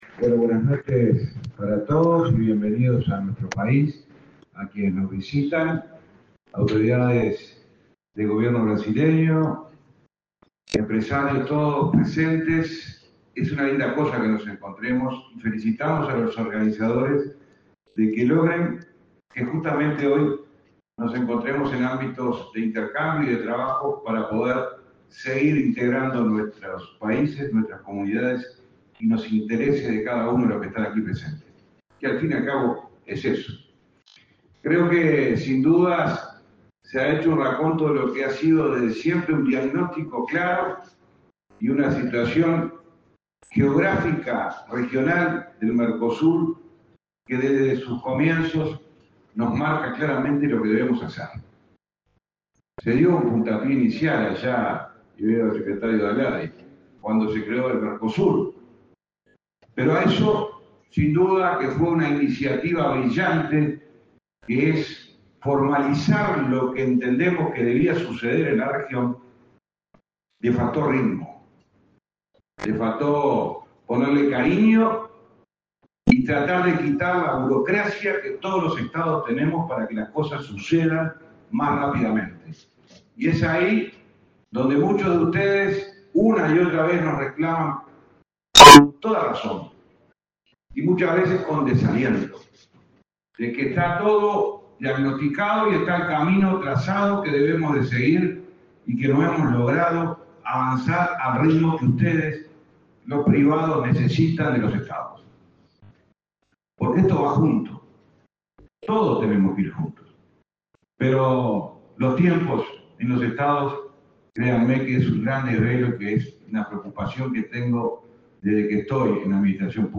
Palabras del ministro de Transporte y Obras Públicas, José Luis Falero
El ministro de Transporte y Obras Públicas, José Luis Falero, participó, este 15 de agosto, en el evento Mercosur Export 2024.